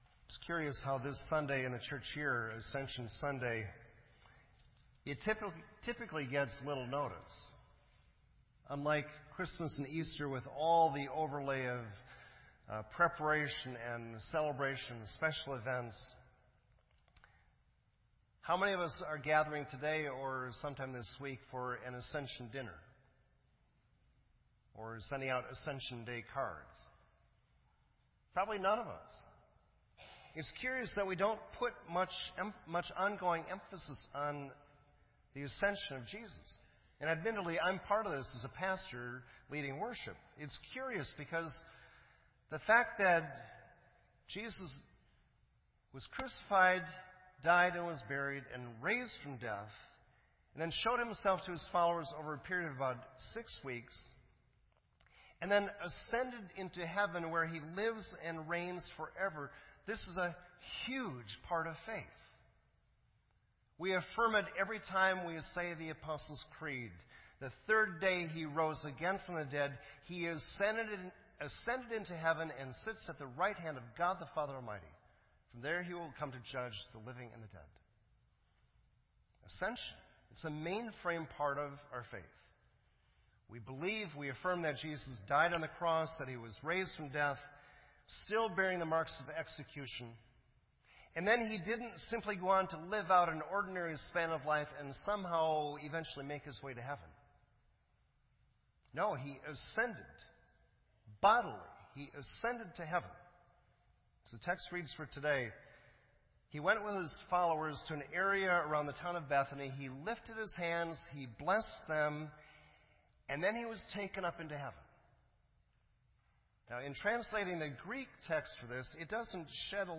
This entry was posted in Sermon Audio on May 30